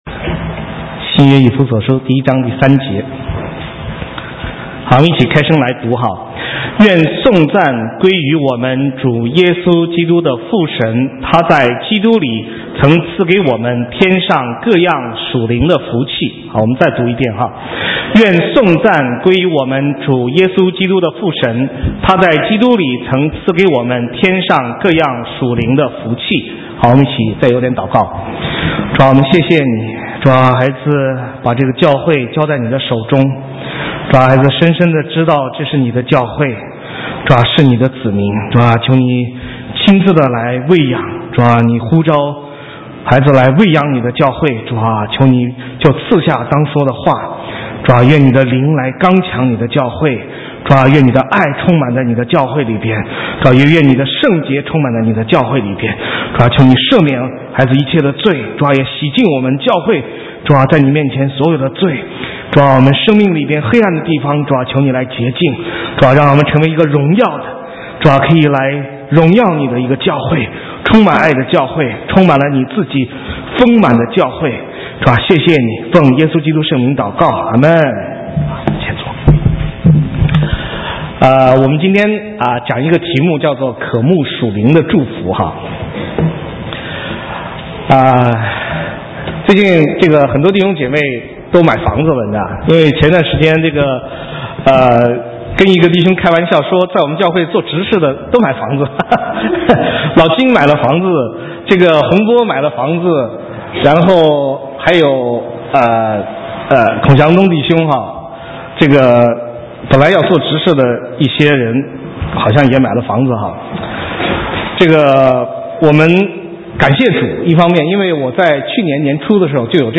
神州宣教--讲道录音 浏览：渴慕属灵的福气(一) (2010-10-17)